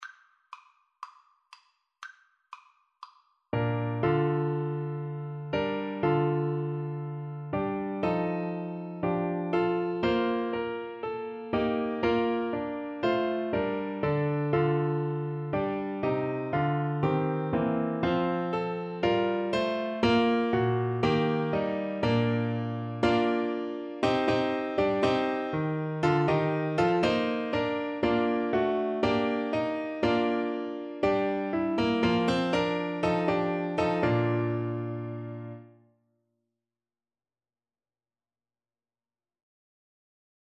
Play (or use space bar on your keyboard) Pause Music Playalong - Piano Accompaniment Playalong Band Accompaniment not yet available reset tempo print settings full screen
Allegro = c. 120 (View more music marked Allegro)
Bb major (Sounding Pitch) C major (Tenor Saxophone in Bb) (View more Bb major Music for Tenor Saxophone )
4/4 (View more 4/4 Music)